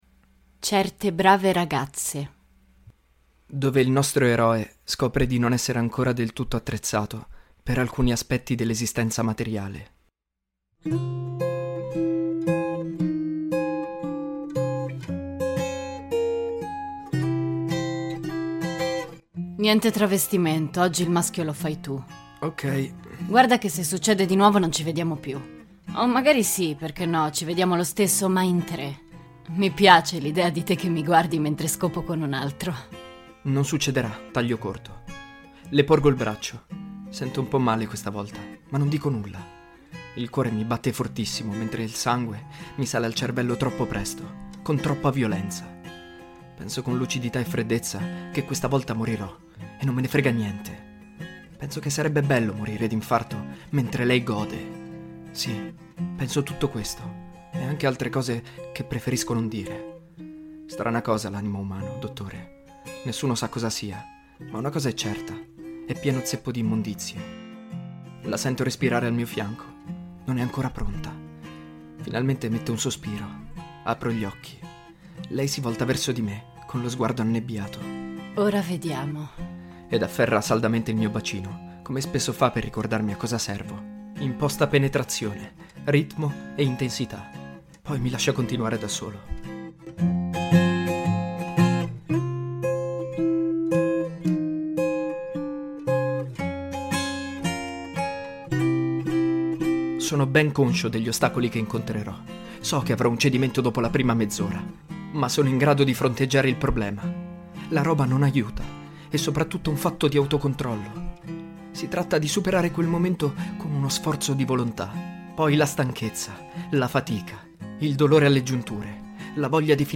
Nel corso dell'episodio si possono ascoltare una cover di "Michelle" dei Beatles e "Gouge Away" dei Pixies (versione strumentale e live).
During the episode you can hear a cover of "Michelle" by The Beatles and "Gouge Away" by The Pixies (instrumental and live).